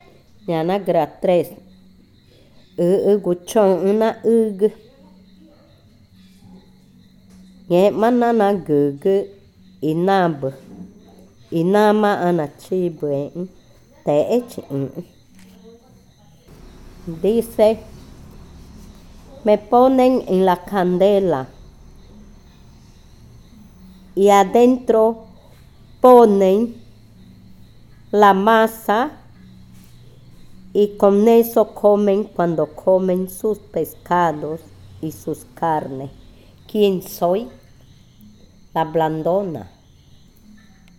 Adivinanza 17. La blandona